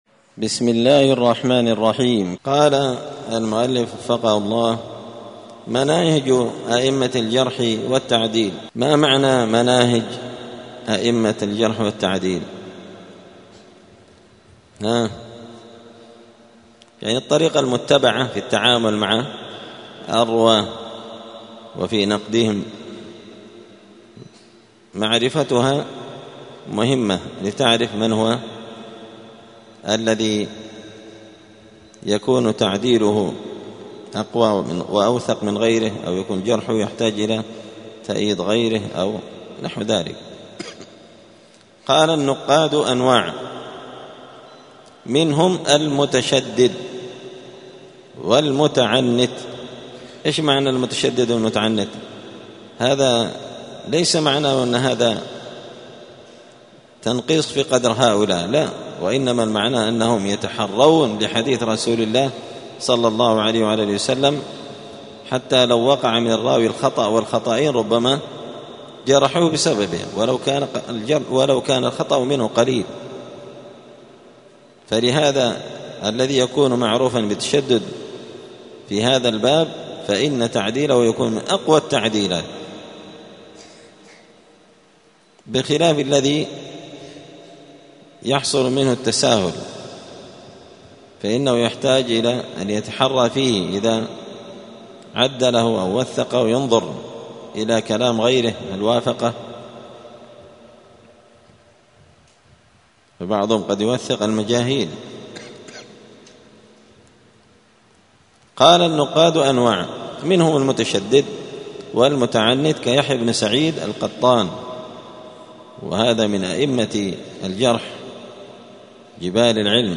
*الدرس الحادي والسبعون (71) باب مناهج أئمة الجرح والتعديل*